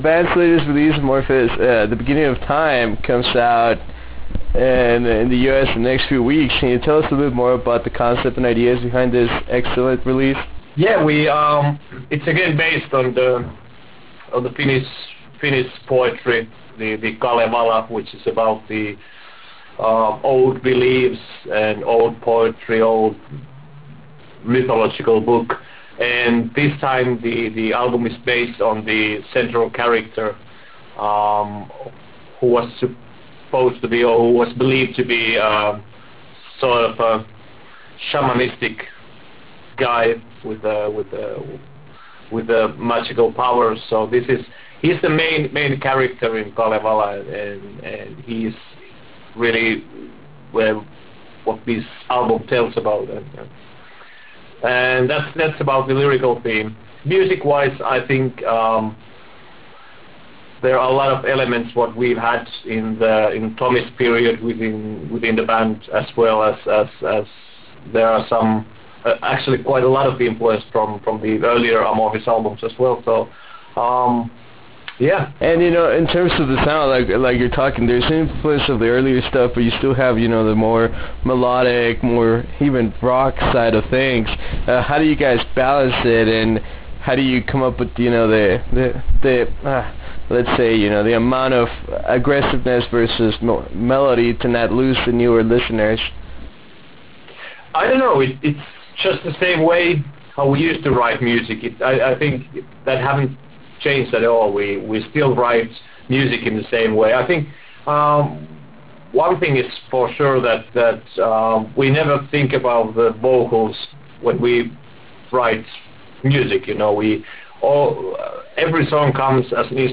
Interview with Esa Holopainen of Amorphis
Interview with Esa Holopainen - Amorphis.wav